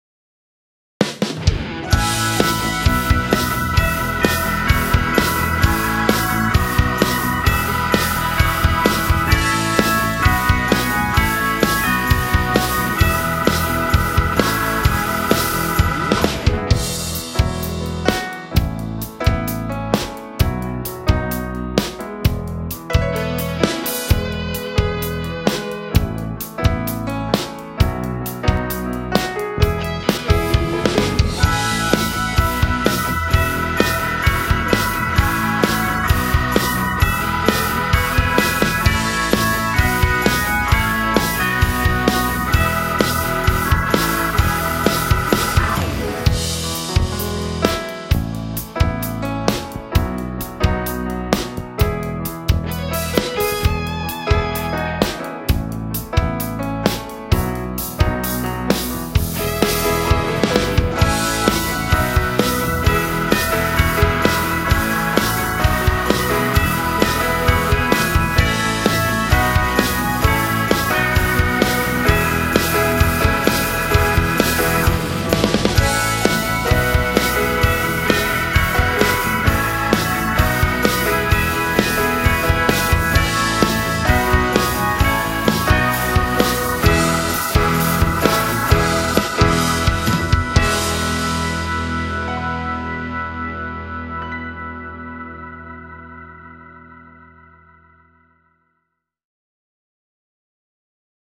sem voz